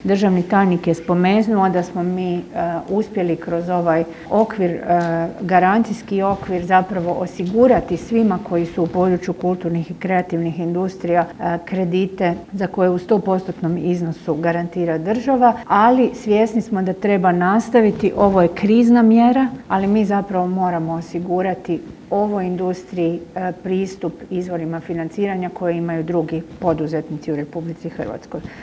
ZAGREB - U organizaciji AMM GLOBAL INSTITUTA održan je prvi CROATIA MEDIA CONGRESS na temu izmjena Zakona o elektroničkim medijima.
Slušajući probleme s kojima se suočavaju vodeći, a drugačije nije ni s manjim medijima, ministrica kulture i medija Nina Obuljen Koržinek je poručila: